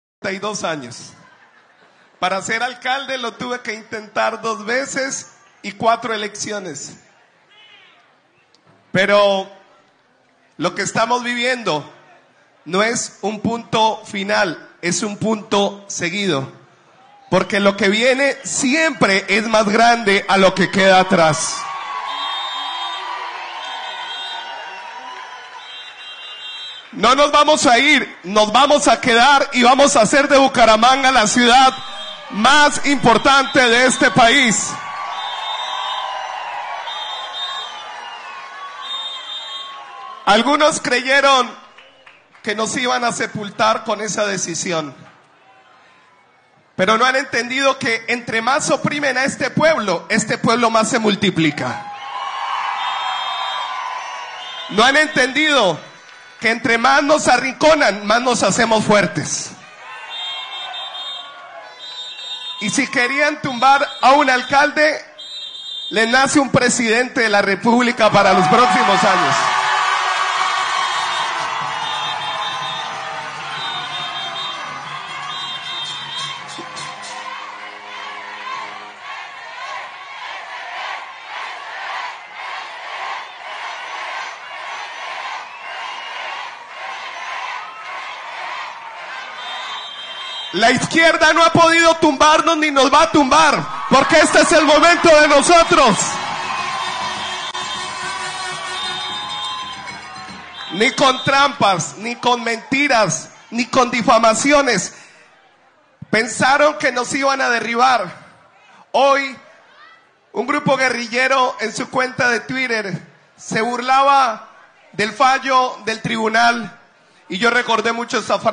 En medio de la rendición de cuentas, el alcalde de Bucaramanga, Jaime Andrés Beltrán aludió a la decisión en primera instancia del Tribunal Administrativo de Santander de anular su elección como mandatario de la capital santandereana.